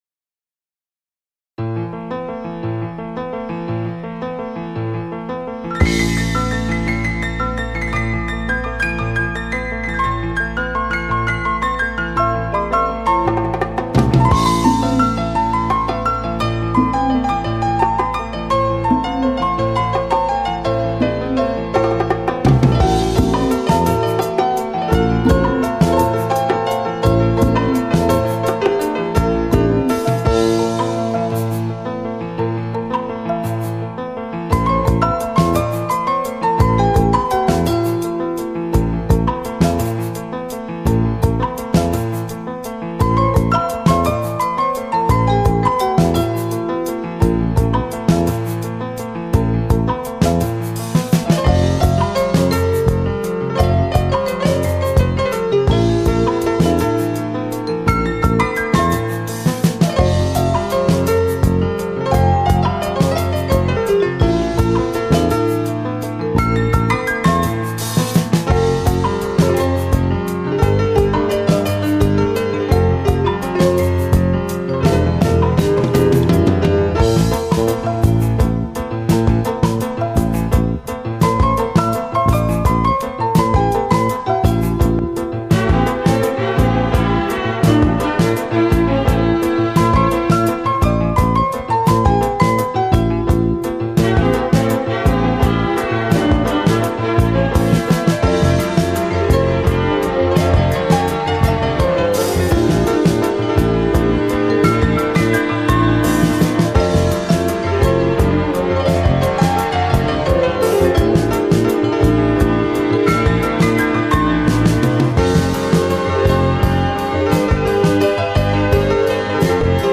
Cette pièce instrumentale